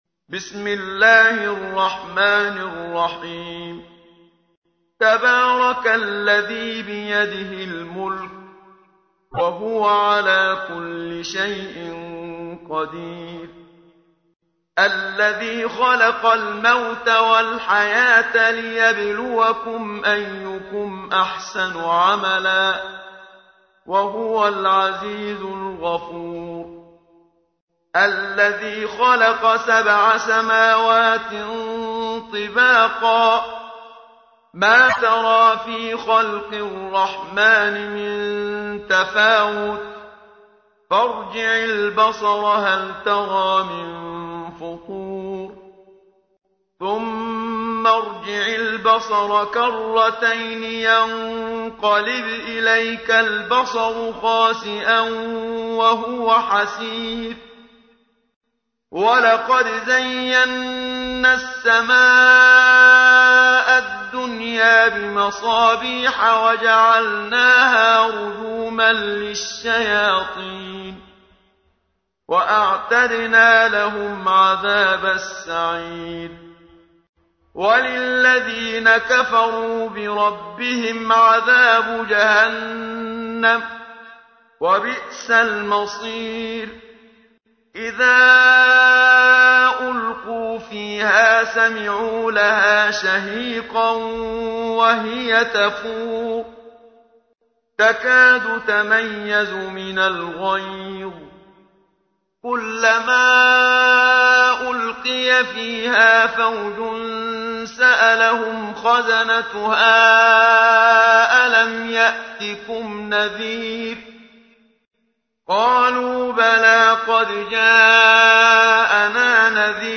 تحميل : 67. سورة الملك / القارئ محمد صديق المنشاوي / القرآن الكريم / موقع يا حسين